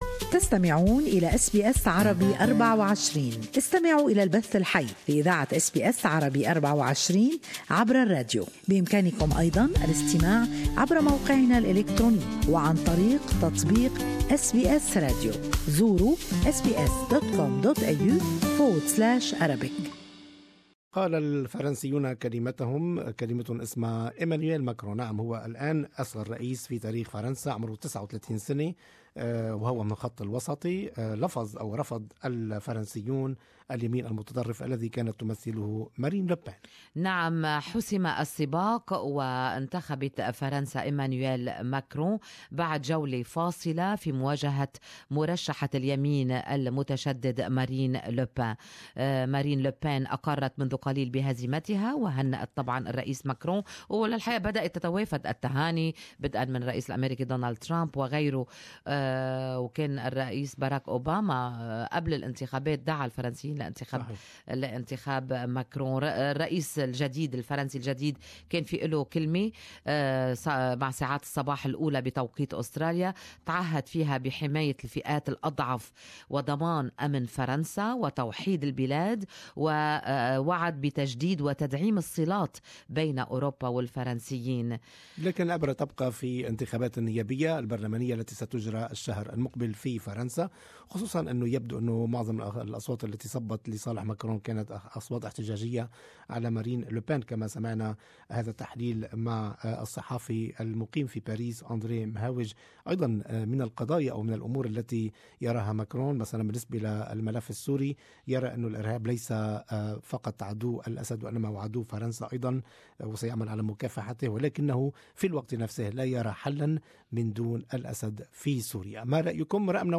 Good Morning Australia listeners share their opinions on the results of the French election.